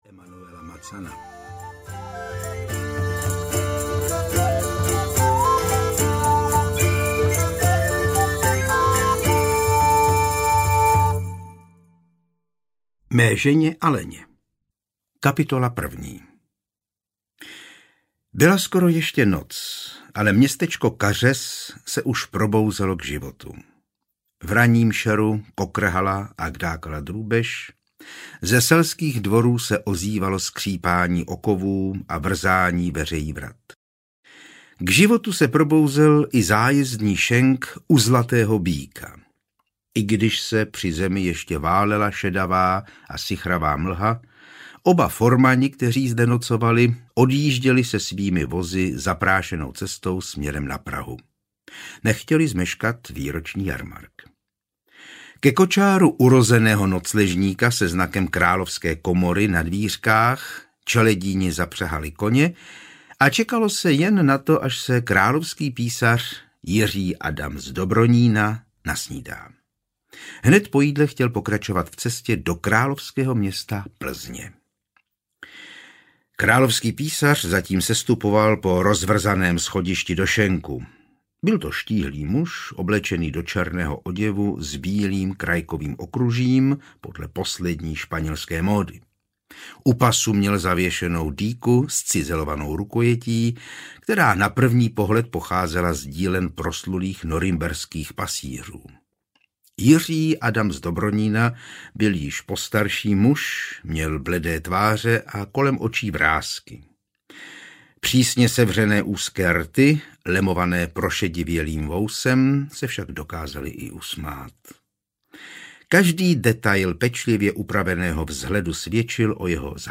Plzeňské mordy audiokniha
Ukázka z knihy